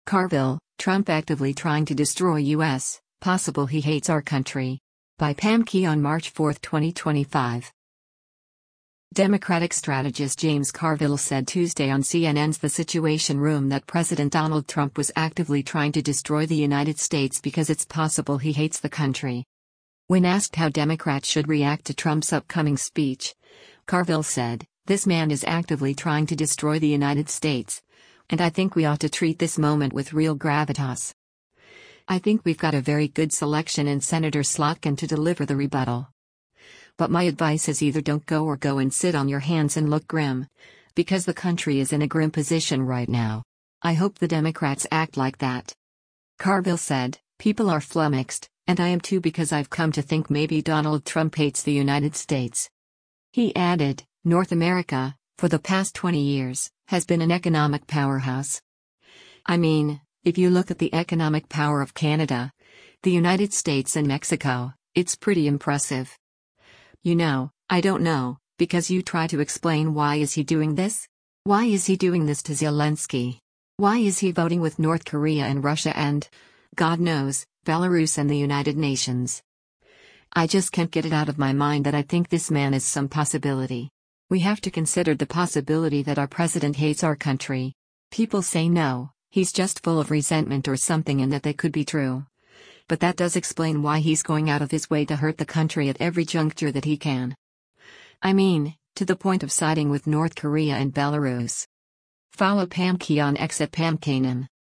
Democratic strategist James Carville said Tuesday on CNN’s “The Situation Room” that President Donald Trump was “actively trying to destroy” the United States because it’s possible he “hates” the country.